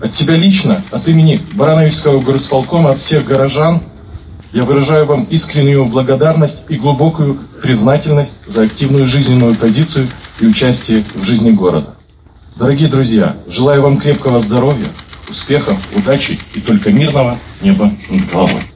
В Барановичах у памятника воинам-интернационалистам собрались ветераны-афганцы, члены их семей, матери и вдовы погибших, руководство города, представители общественных организаций, предприятий, силовых структур, военнослужащие, духовенство, молодежь, жители города. Открывая митинг, председатель горисполкома Максим Антонюк подчеркнул, что 15 февраля – это не просто дата, а особенный день для каждого, кто прошел через Афганистан. Максим Анатольевич обратил внимание на ценность мира в непростой геополитической обстановке, а также отметил большой вклад воинов-интернационалистов  в его укрепление и патриотическое воспитание молодежи.